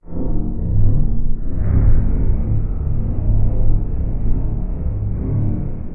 lava_amb_01_quiet.ogg